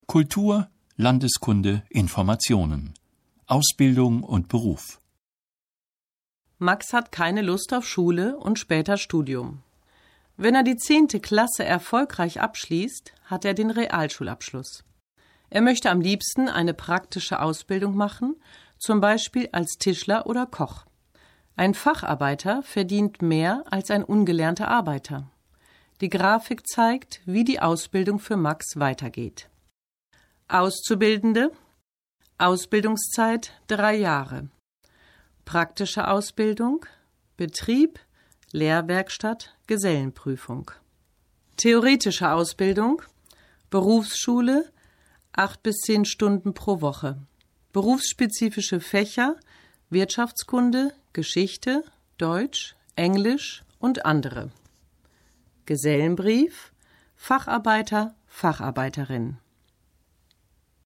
Situation 10 – Minidialoge (3663.0K)